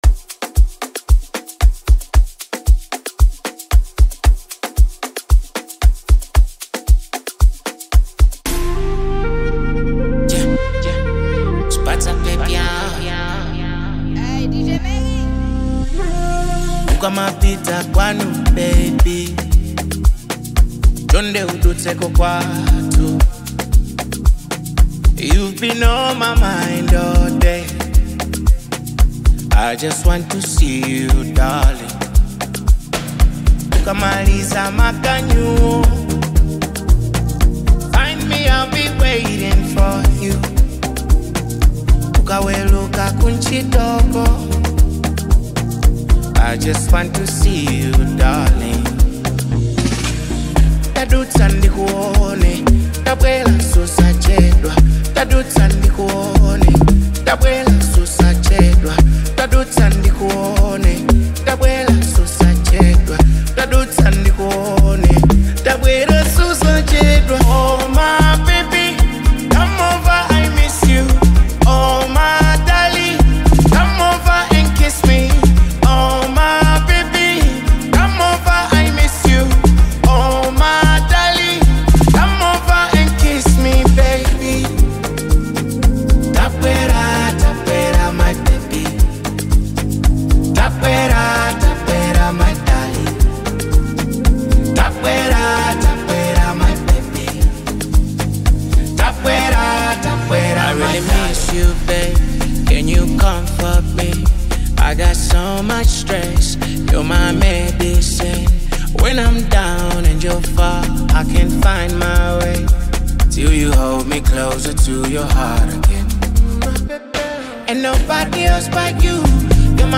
Malawian Amapiano